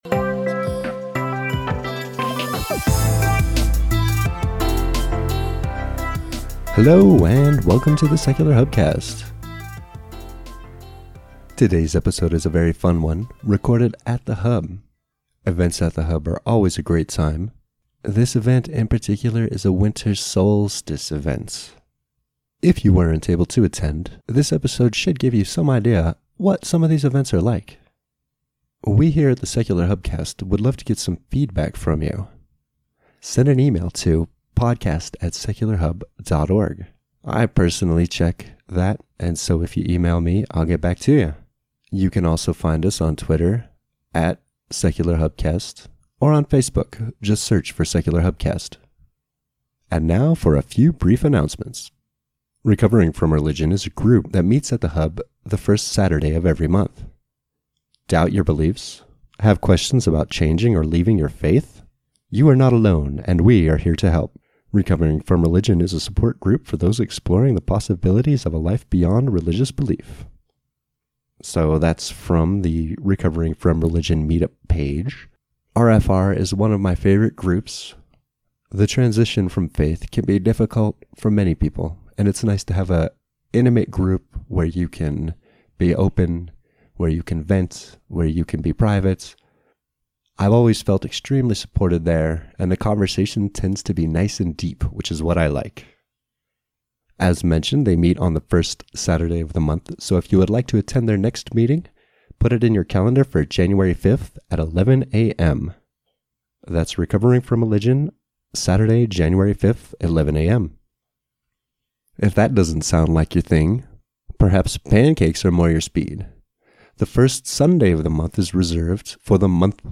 It’s also a party, and you can hear all the fun and merriment that Hubsters are known for!